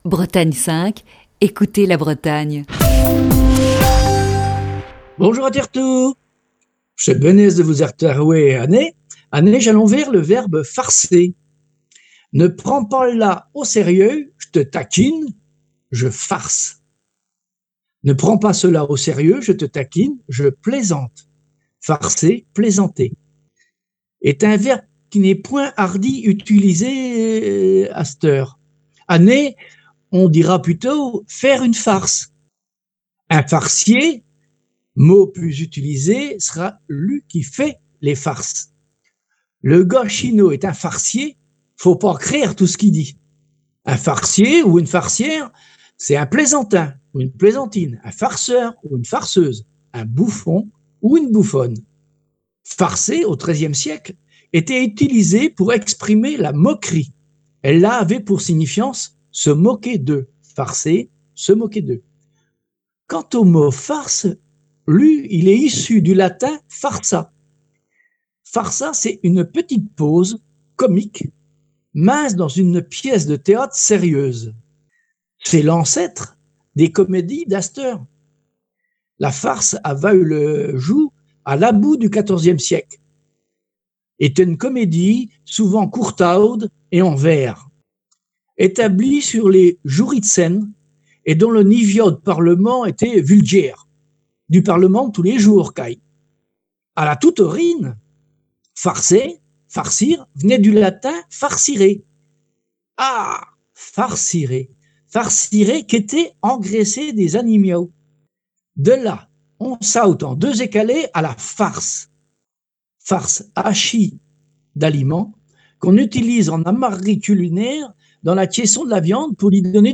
Chronique du 20 avril 2020.